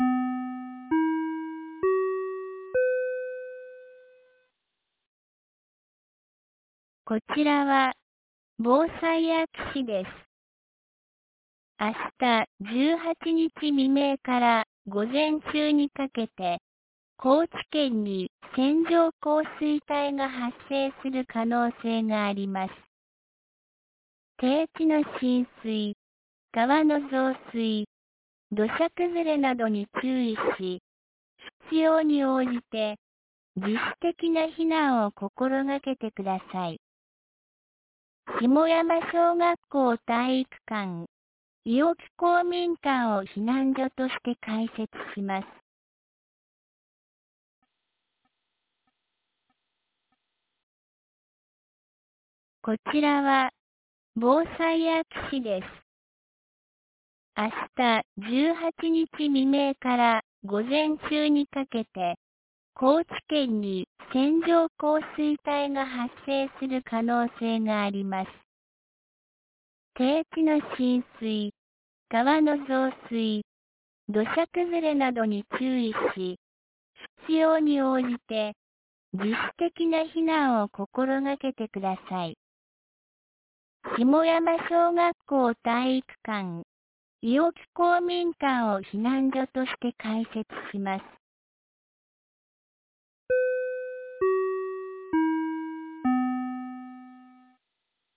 2024年06月17日 16時46分に、安芸市より伊尾木、下山へ放送がありました。